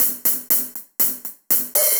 Index of /musicradar/ultimate-hihat-samples/120bpm
UHH_AcoustiHatA_120-04.wav